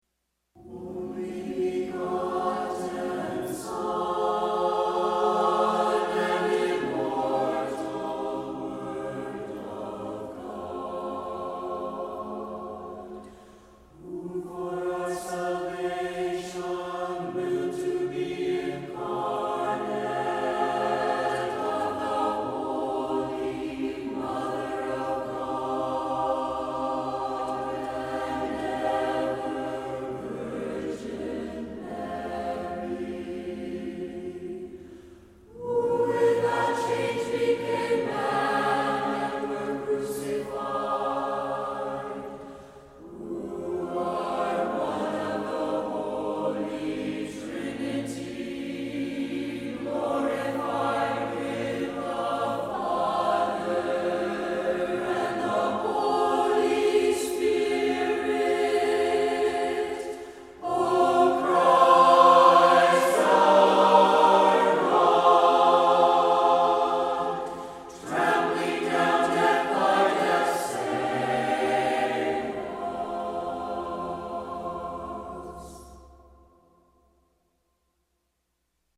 I absolutely love singing this song during Divine Liturgy.